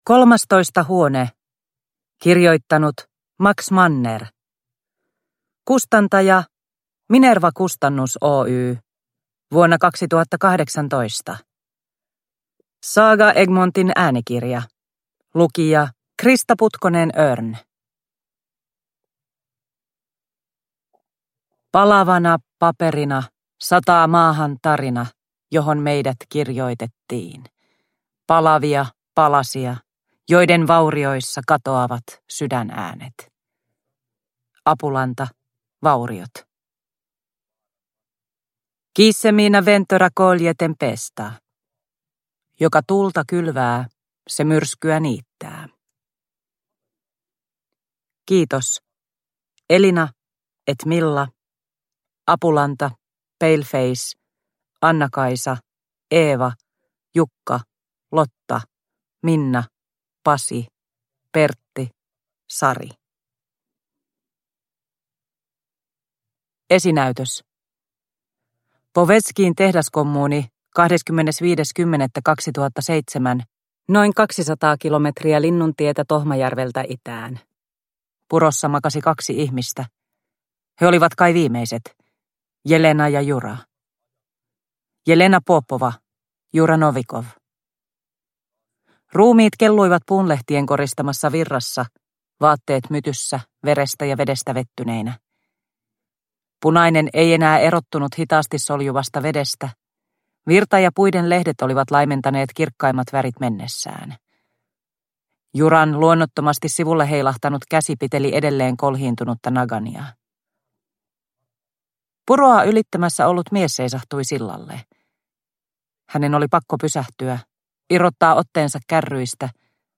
Huone / Ljudbok